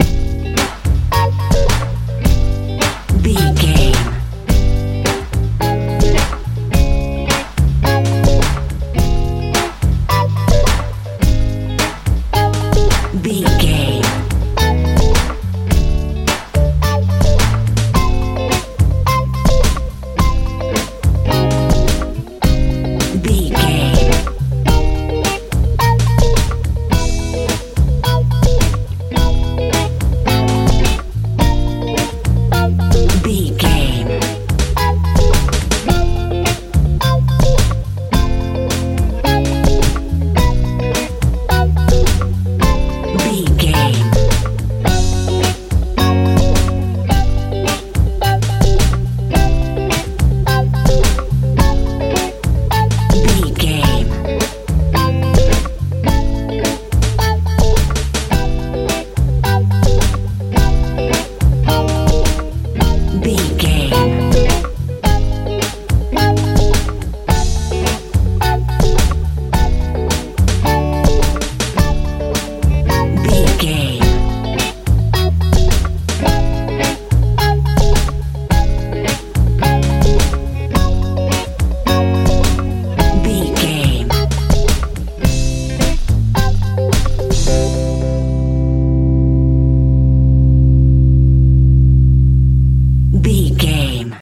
funk pop feel
Aeolian/Minor
C♯
groovy
funky
synthesiser
electric guitar
bass guitar
drums
80s
90s